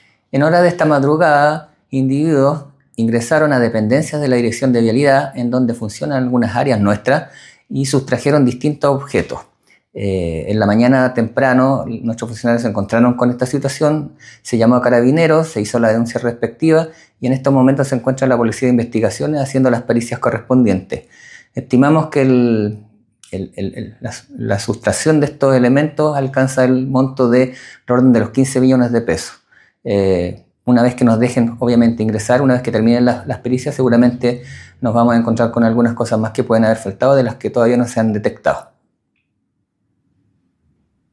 El director regional de Vialidad, Marcelo Muñoz, se refirió al robo en la repartición del Ministerio de Obras Públicas, avaluado preliminarmente en alrededor de $15 millones.